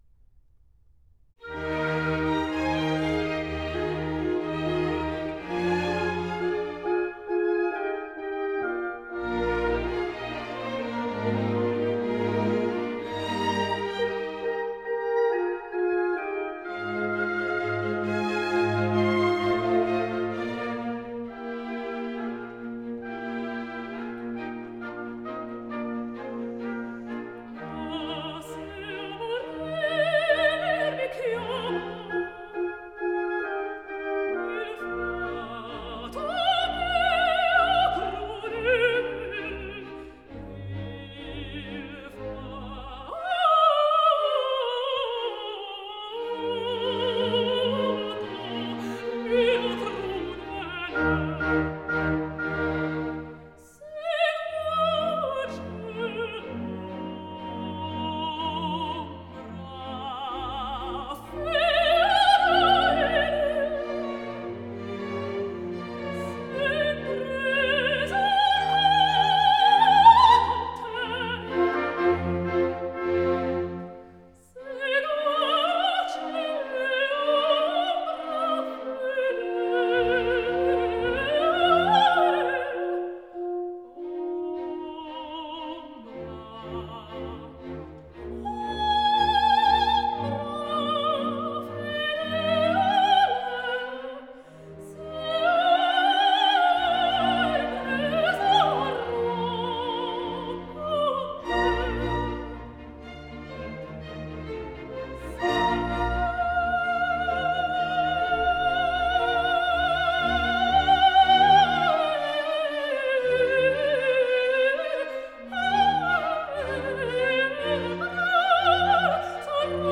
» 6 - Arias, Vocal Ensembles, Canons